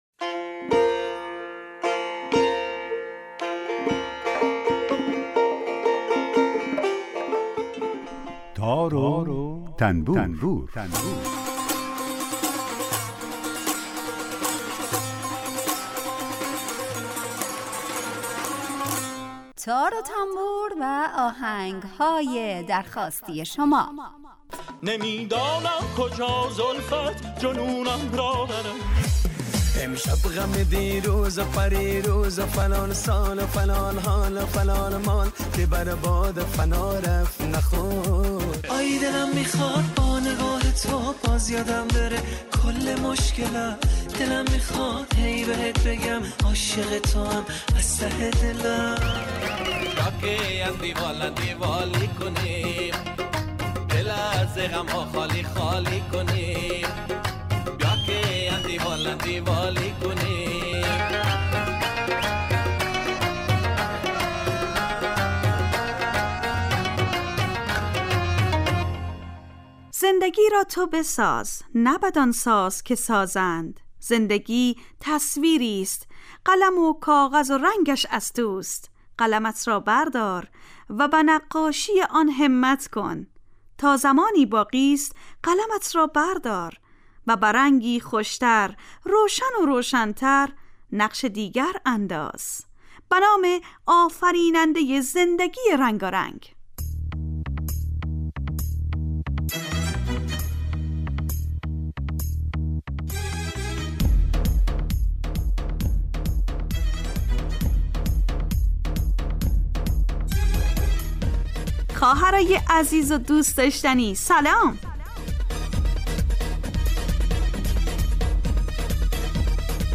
برنامه تار و تنبور هر روز از رادیو دری به مدت 30 دقیقه برنامه ای با آهنگ های درخواستی شنونده ها کار از گروه اجتماعی رادیو دری.